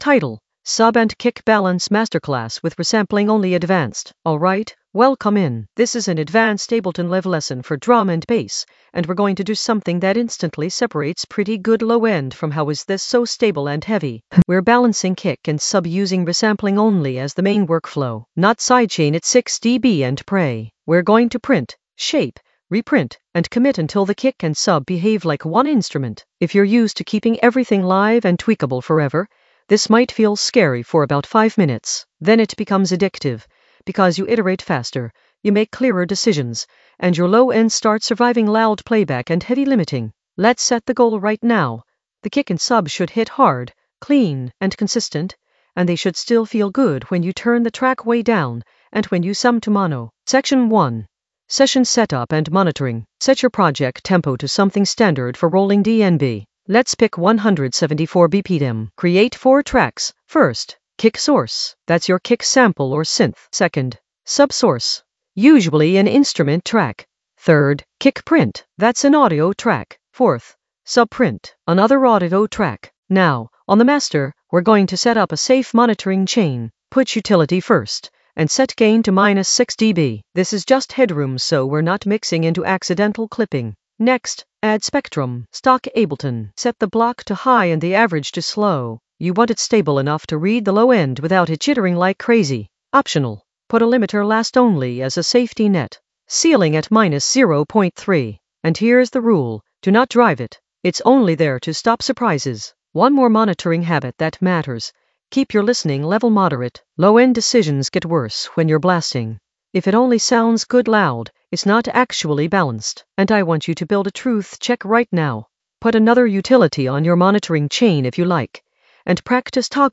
Narrated lesson audio
The voice track includes the tutorial plus extra teacher commentary.
An AI-generated advanced Ableton lesson focused on Sub and kick balance masterclass with resampling only in the Mixing area of drum and bass production.
sub-and-kick-balance-masterclass-with-resampling-only-advanced-mixing.mp3